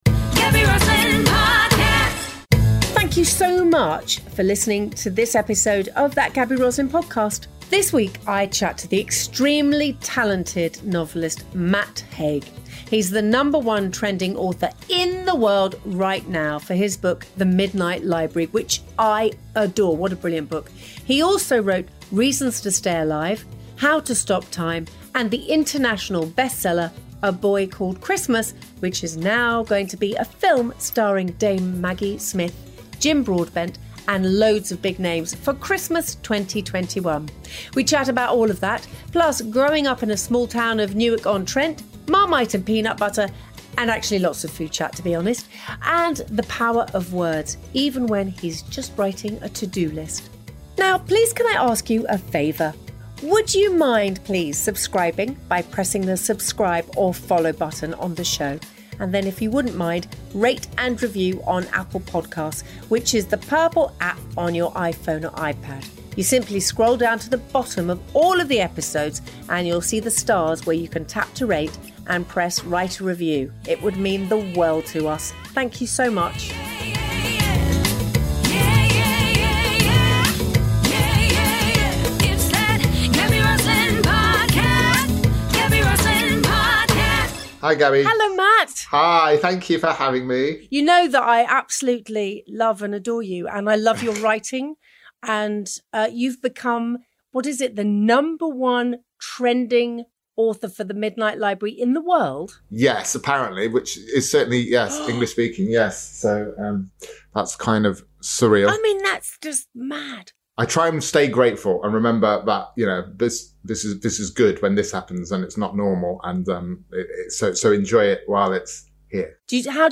In this episode Gaby chats to novelist Matt Haig who is the number one trending author in the world right now for his book ‘The Midnight Library’.